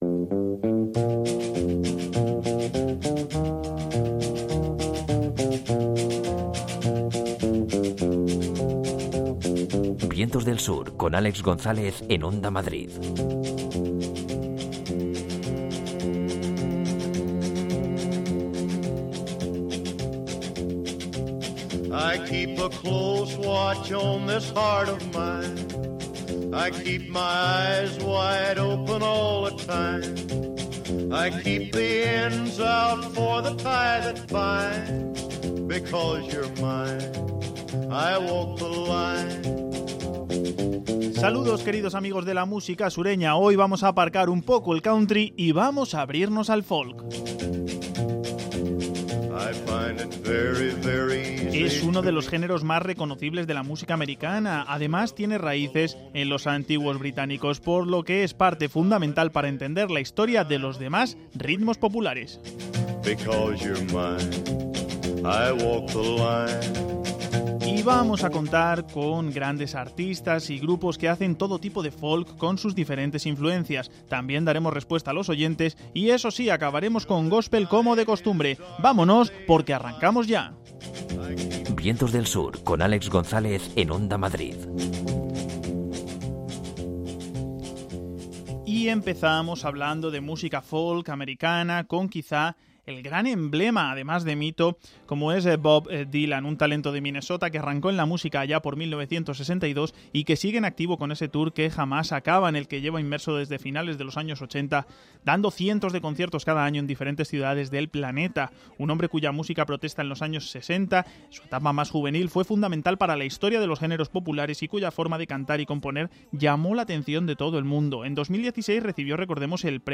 Acaba con góspel y el espacio de consultas de los oyentes.